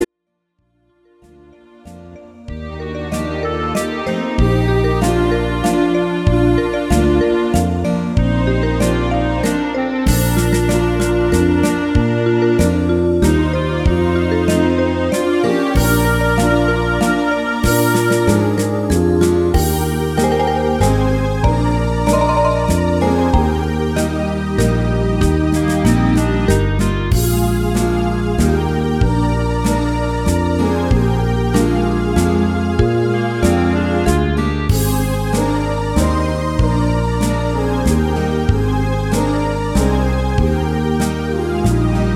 Rubrika: Pop, rock, beat
- waltz